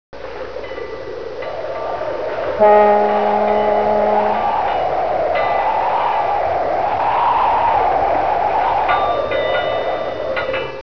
docks.wav